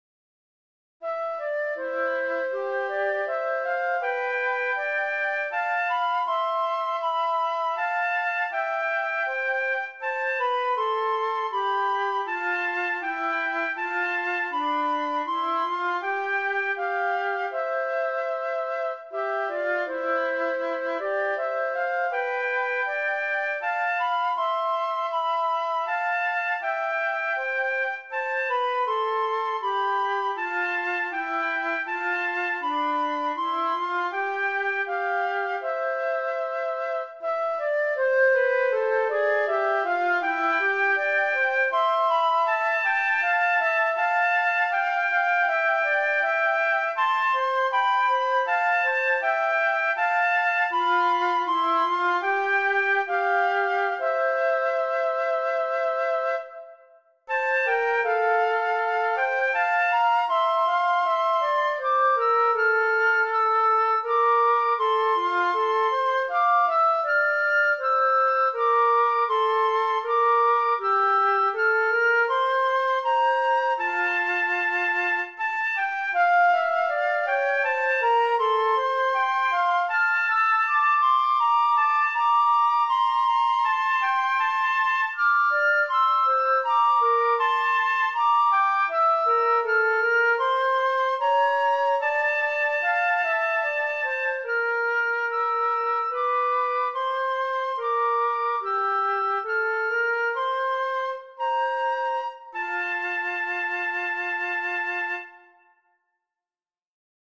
フルート二重奏（フルート2本）
２コーラスですが、2回目は転調させてます。
イントロが無いのは、慰問演奏で一緒に歌ってもらうとか想定してないからです。
最後のフレーズを繰り返してエンディングにしてあります。